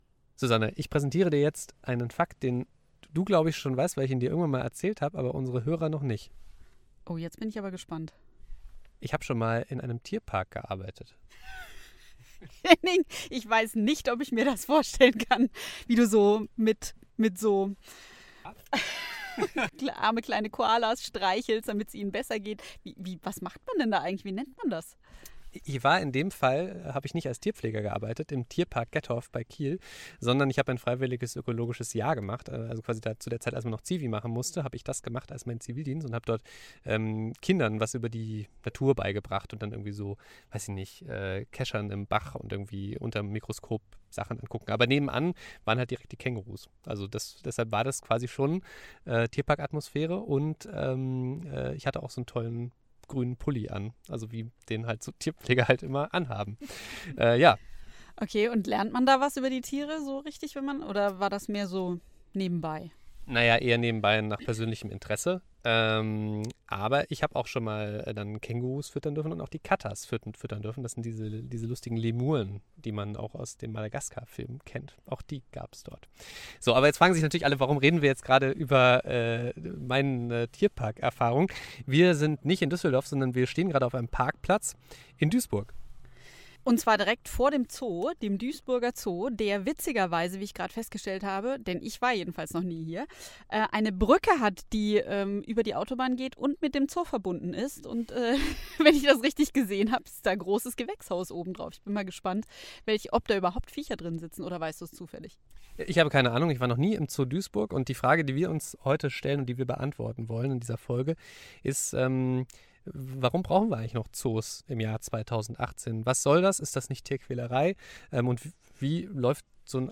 im Zoo Duisburg hinter den Kulissen